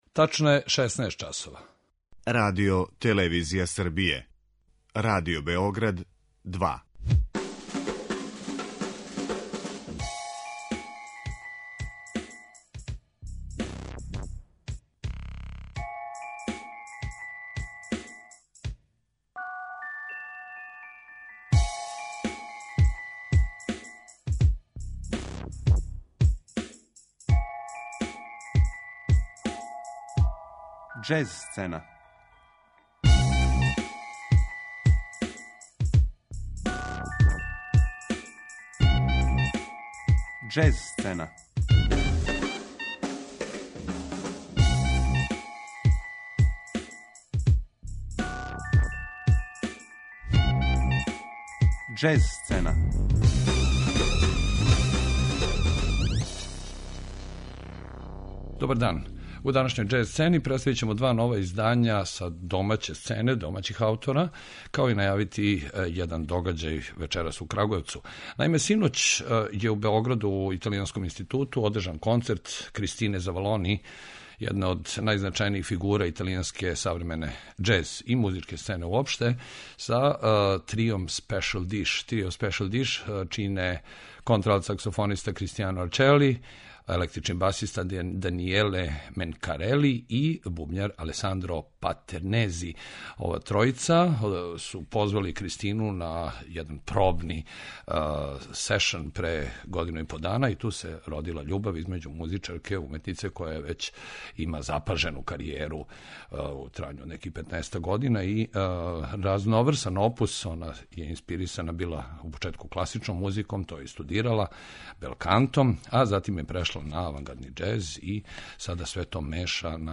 саксофонисткиња
бас гитариста